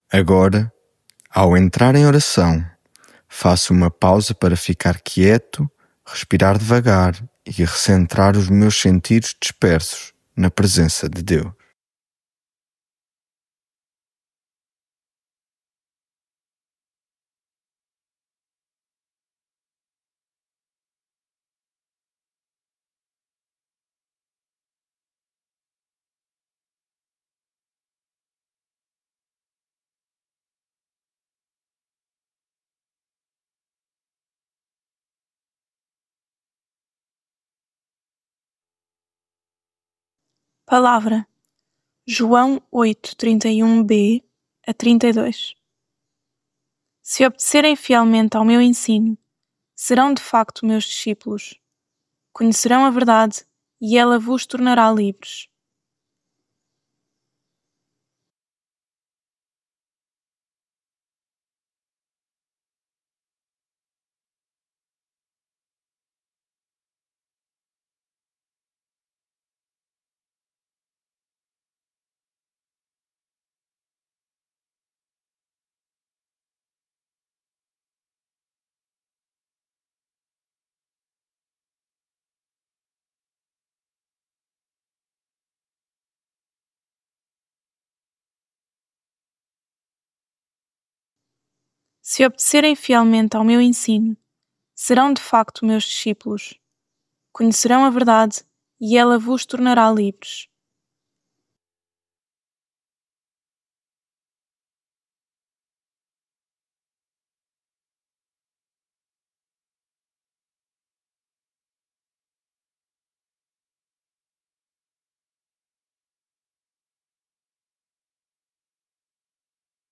Devocional
lectio divina